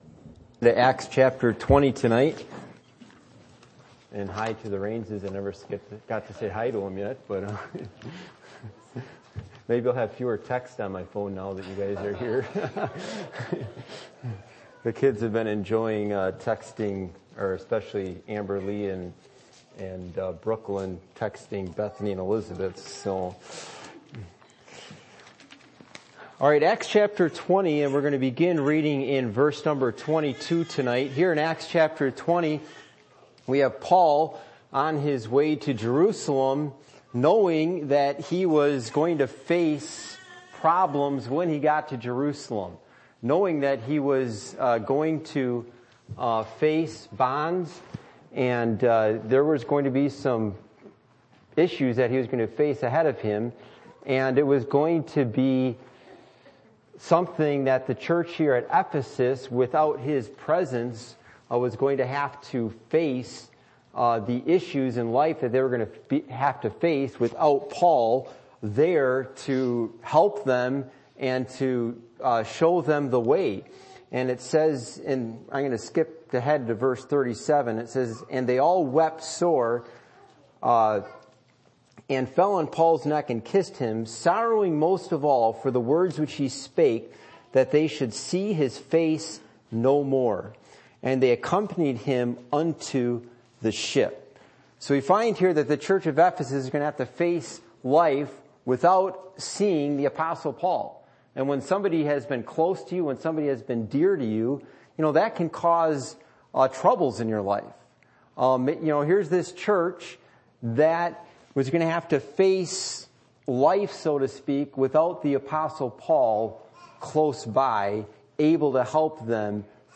Passage: Acts 20:22-38 Service Type: Midweek Meeting